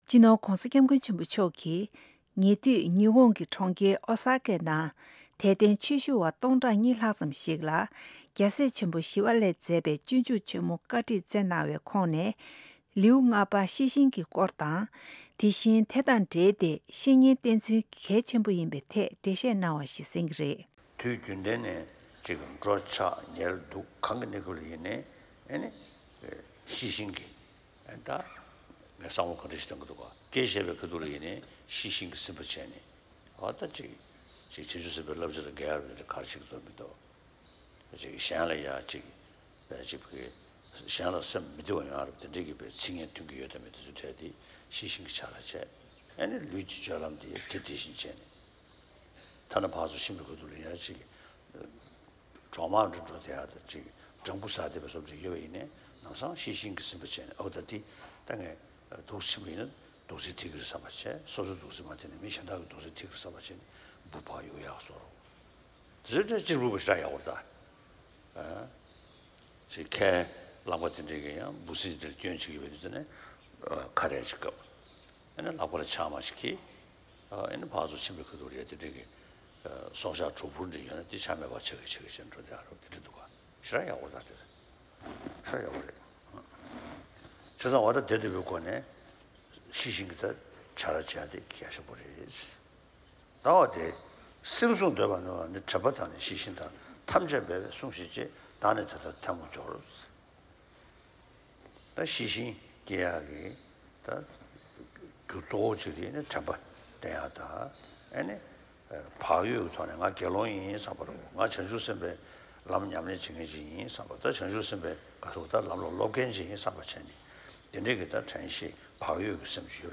ཉེ་དུས་བོད་ཀྱི་བླ་ན་མེད་པའི་དབུ་ཁྲིད་སྤྱི་ནོར་༧གོང་ས་སྐྱབས་མགོན་ཆེན་པོ་མཆོག་གིས་ཉི་ཧོང་གི་གྲོང་ཁྱེར་ཨོ་ས་ཀའི་ནང་དད་ལྡན་ཆོས་ཞུ་བ་སྟོང་ཕྲག་གཉིས་ལྷག་ཙམ་ཞིག་ལ་སྤྱོད་འཇུག་ཆེན་མོ་བཀའ་ཁྲིད་གནང་བའི་སྐབས་ཤེས་བཞིན་གྱི་ལེའུའི་སྐོར་དང་ དེ་བཞིན་དགེ་བའི་བཤེས་གཉེན་མཚན་ཉིད་ཚང་བ་ཞིག་དགོས་རྒྱུུ་ཧ་ཅང་གལ་ཆེན་པོ་ཡིན་ལུགས་བཀའ་གནང་བ་ཞིག་གསན་གྱི་རེད།